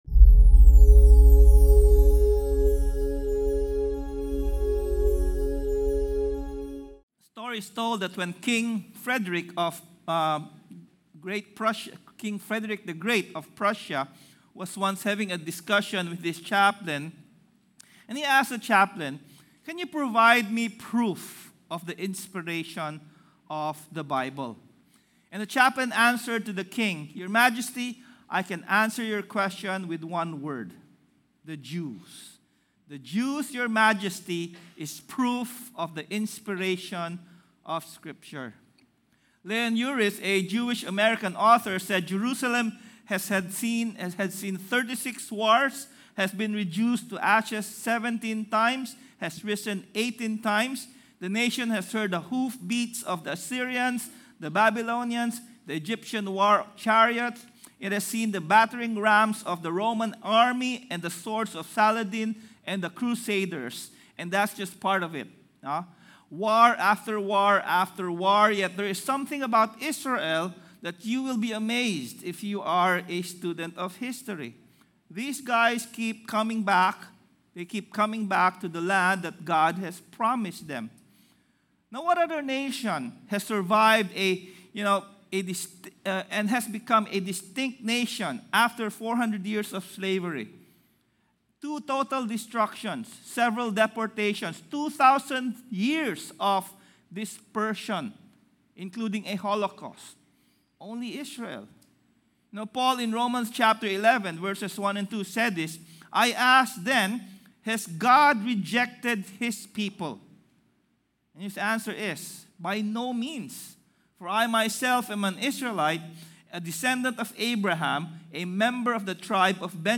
God’s unconditional grace is received not by a perfect life but by humble repentance and faith. Sermon Title: WHY ISRAEL MATTERS